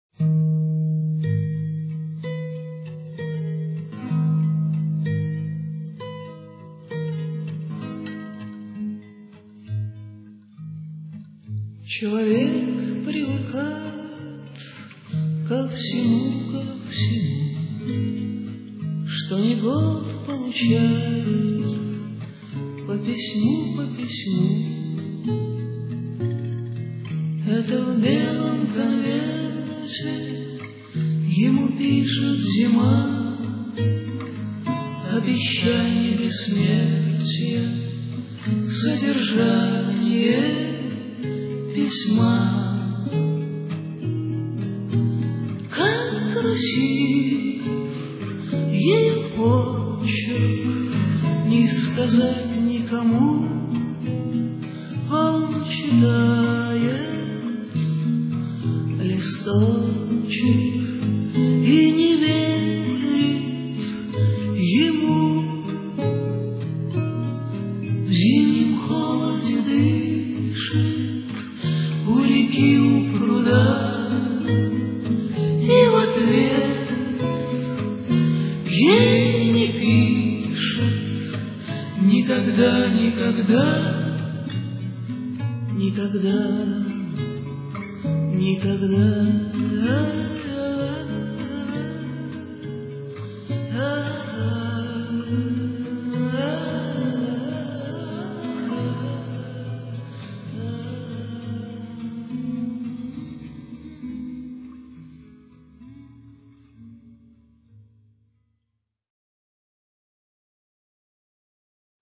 они вместе поют